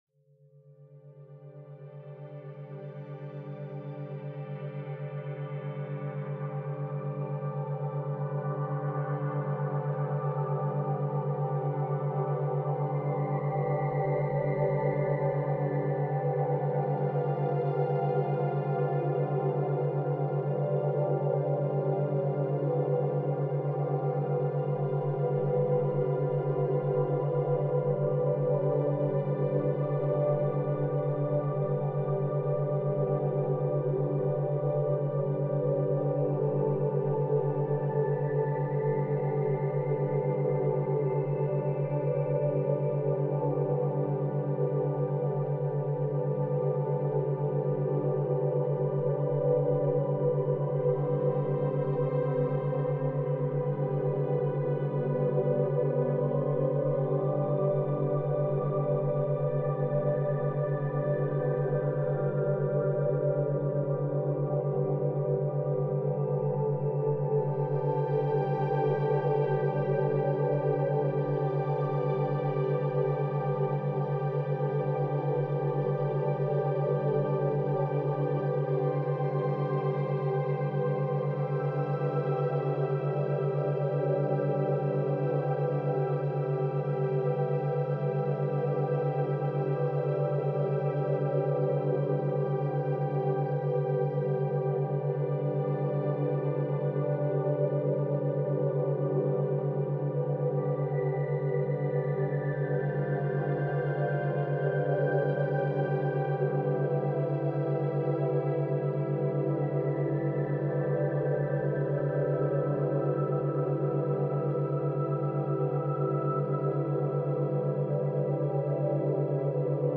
勉強BGM